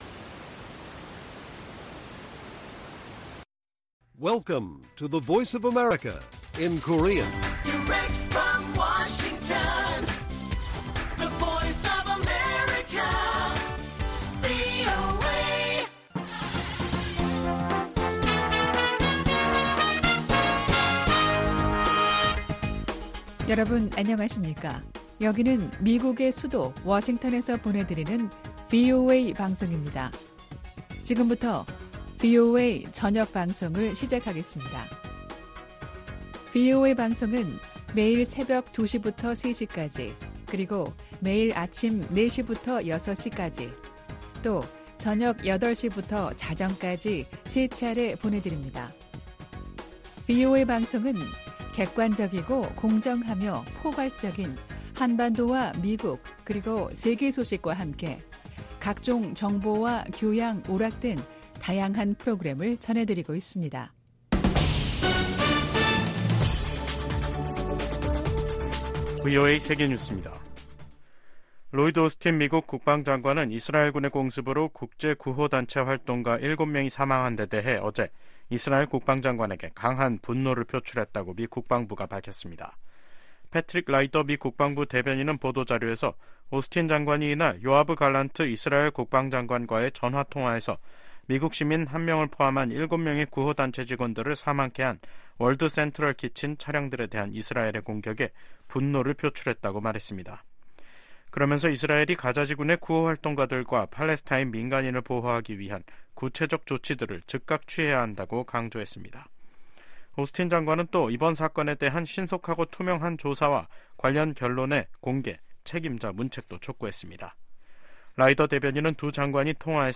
VOA 한국어 간판 뉴스 프로그램 '뉴스 투데이', 2024년 4월 4일 1부 방송입니다. 미국 백악관과 국무부는 다음 주 워싱턴에서 열리는 미일 정상회담에서 북한 문제와 미한일 3국 협력 방안 등이 주요 의제가 될 것이라고 밝혔습니다. 미 국방부는 최근 실시된 미한일 3국 연합공중훈련에 대해 3국 협력의 힘을 보여주는 것이라고 평가했습니다.